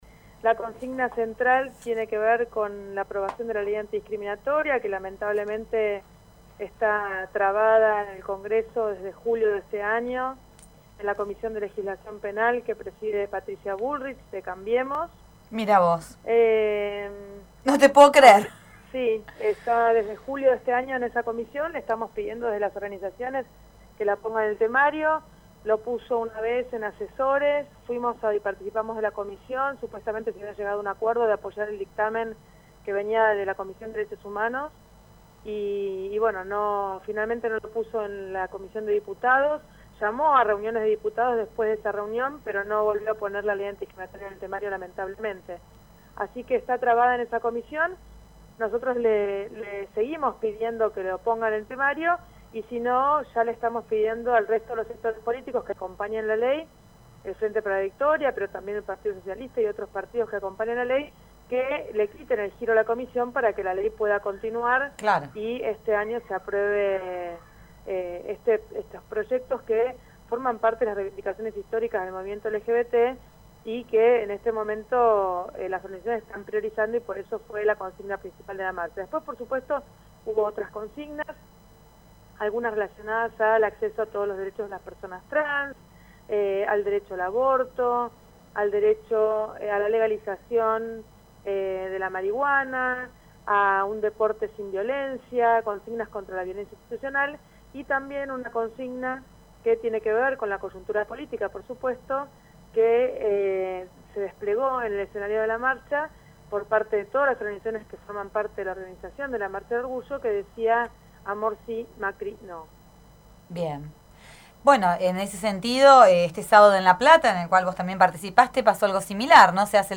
La Viborera conversó con María Rachid, Legisladora Porteña y Secretaria General de la FALGBT (Federación Argentina de Lesbianas, Gays, Bisexuales y Trans) respecto a la marcha del Orgullo de CABA: